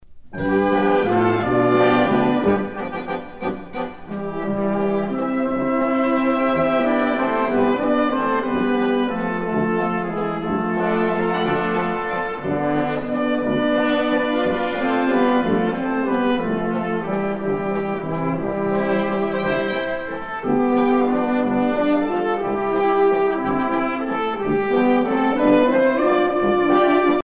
danse : valse lente
Pièce musicale éditée